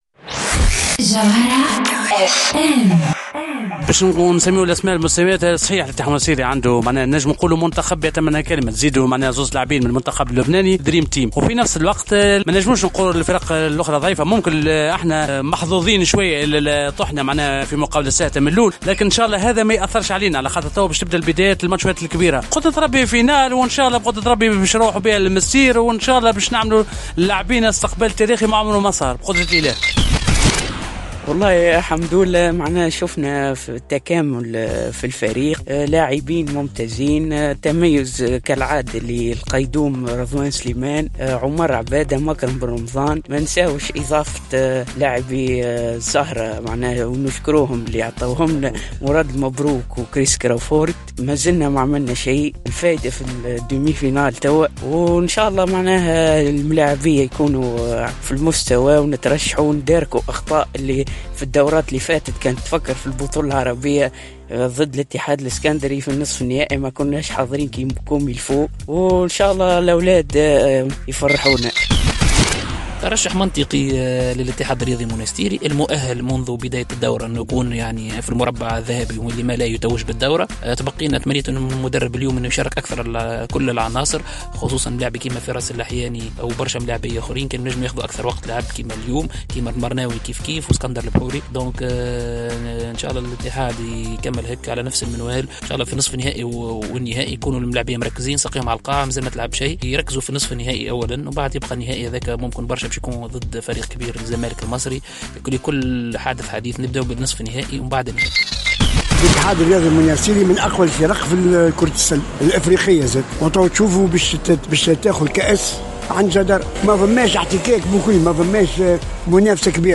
اراء الجماهير الرياضية في المنستير